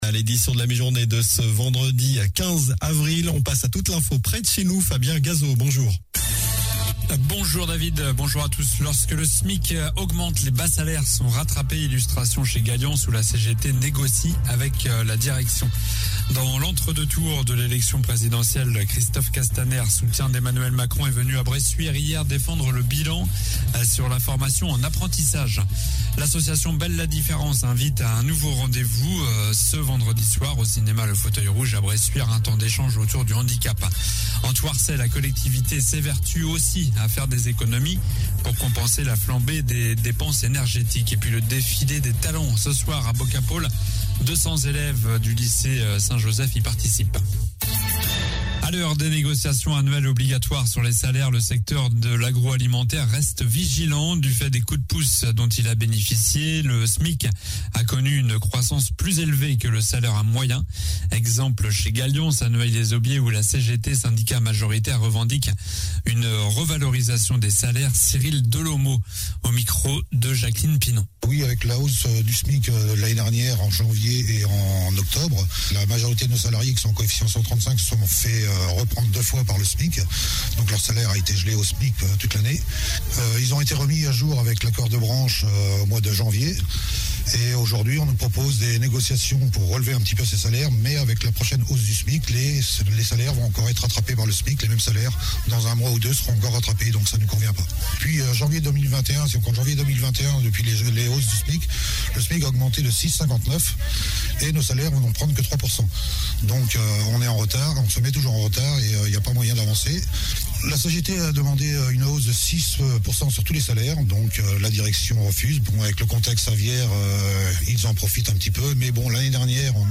Journal du vendredi 16 avril (midi)